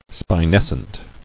[spi·nes·cent]